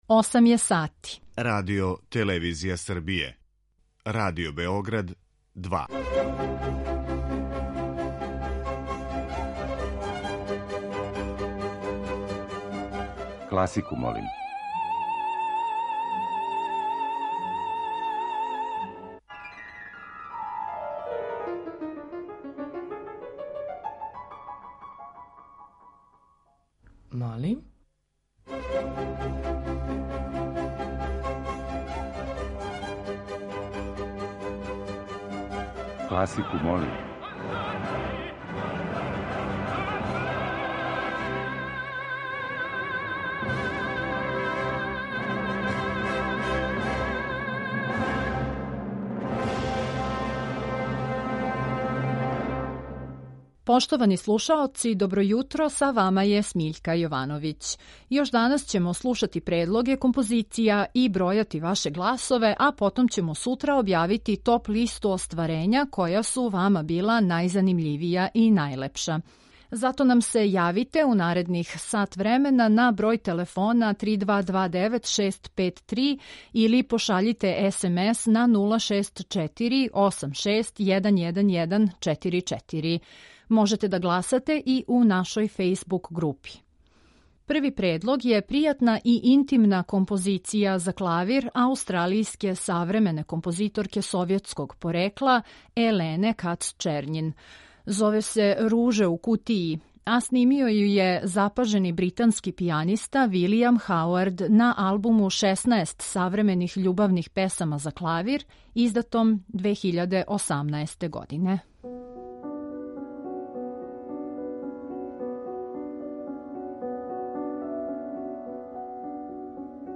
И ове седмице слушаоцима ће бити понуђени разноврсни предлози из домена класичне музике.